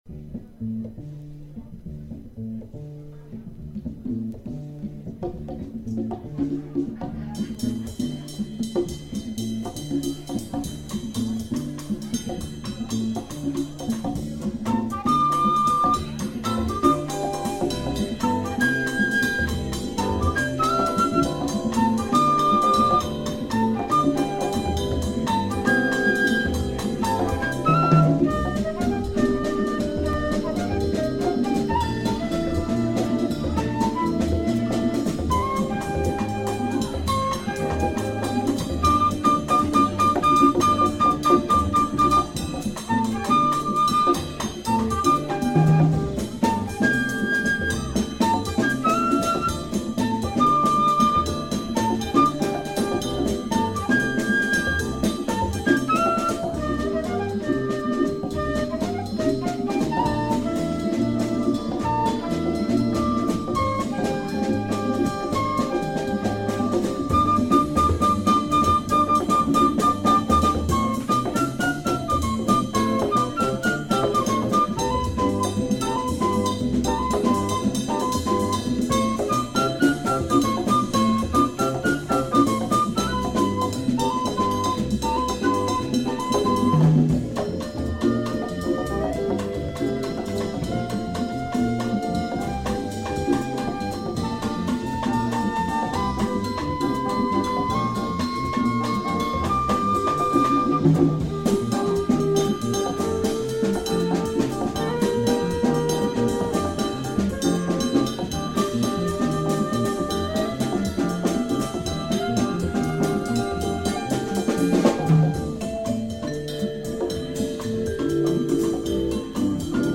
Super rare and cult US jazz album.